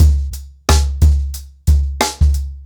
TrackBack-90BPM.47.wav